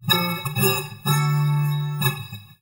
And here is what it sounds like being played through various filters :
Each picture represents the shape of the kernel, "length" is the number of samples on a 44100Hz basis, "passes" is the number of times the filter is applied.